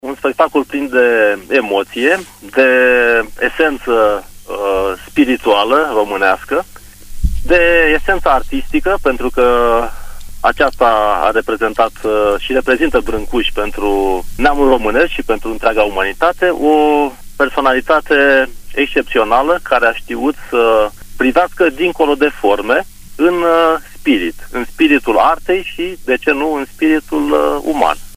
a precizat în emisiunea Pulsul Zilei de la Radio Tg.Mureș: